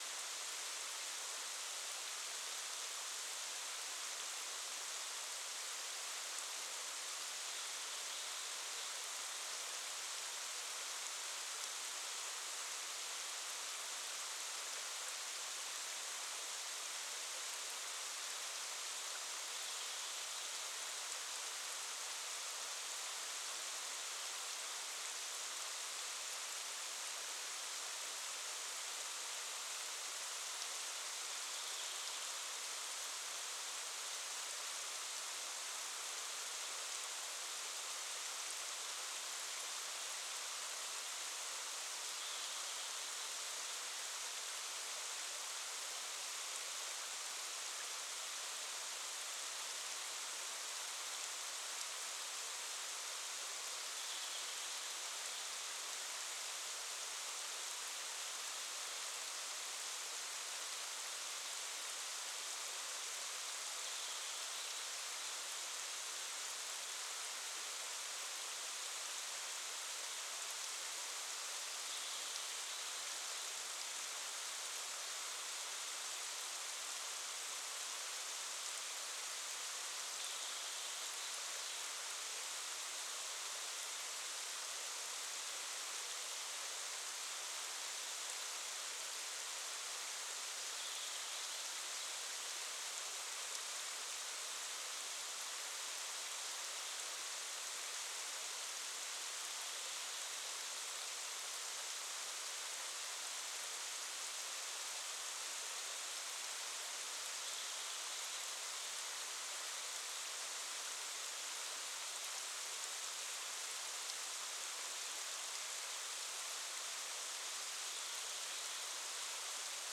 high_band_256kbps.mp3